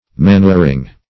Manuring \Ma*nur"ing\, n.